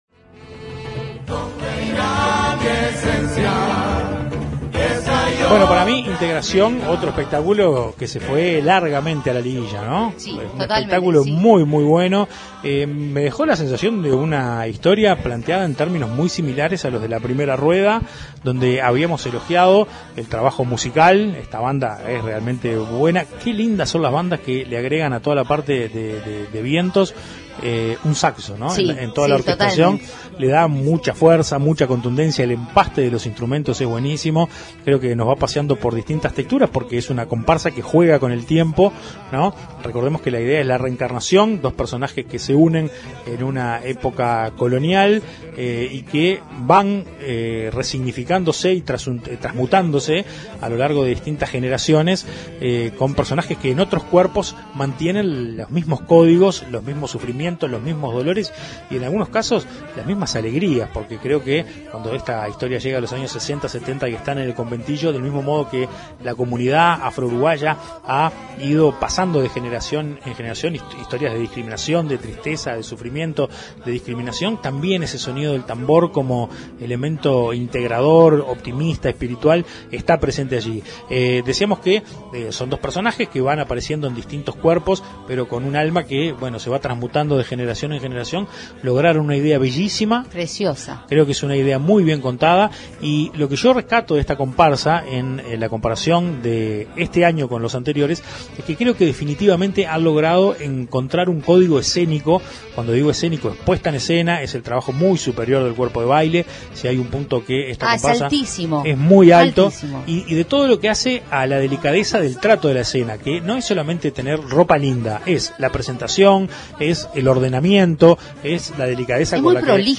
Comparsa-Integracion-Comentarios-.mp3